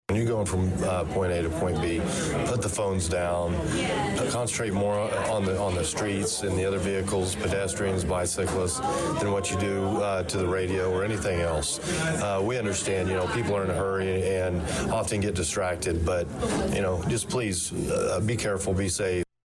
On a serious note, CIMG spoke with Chief Yates about the high number of accidents on the roads lately.  He emphasized that while driving, don’t just think about where you are starting and where you are finishing.